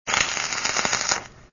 weld2.wav